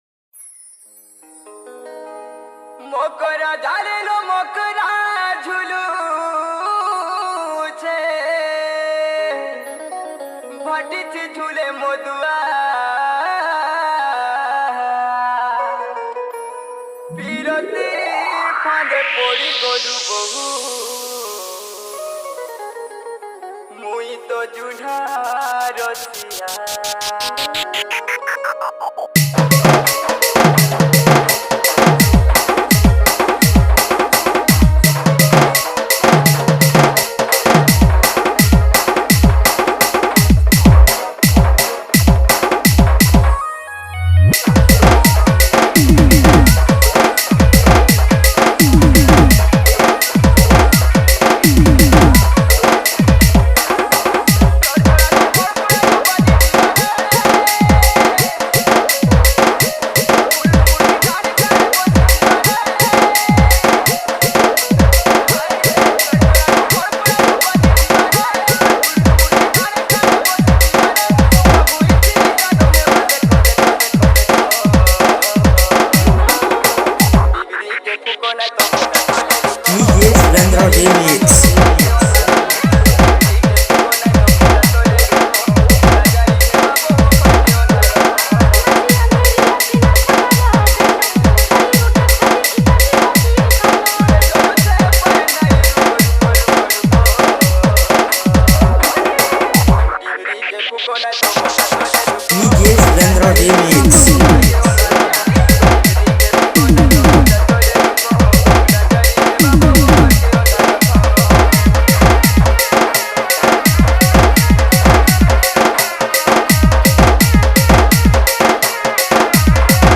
Category:  Old Sambalpuri Dj Song